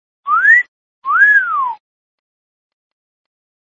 SILBIDO A UNA CHICA
Tonos EFECTO DE SONIDO DE AMBIENTE de SILBIDO A UNA CHICA
Silbido_a_una_chica.mp3